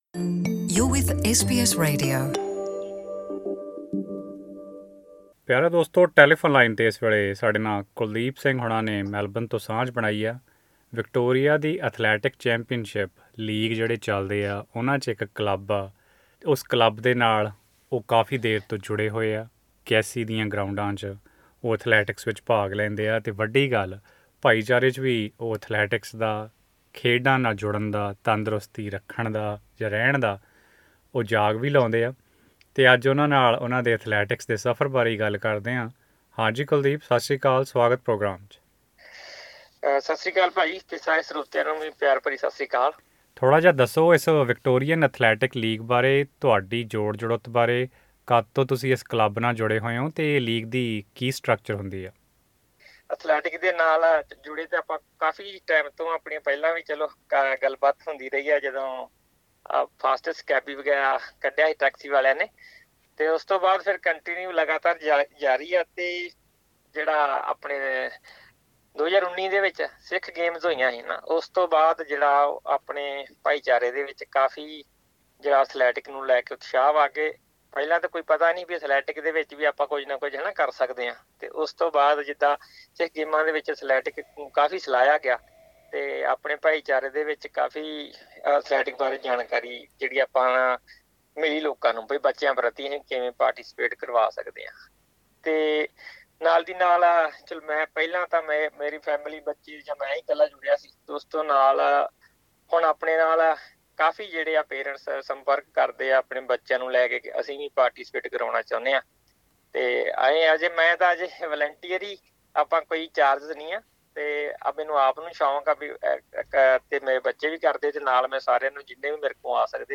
ਆਡੀਓ ਇੰਟਰਵਿਊ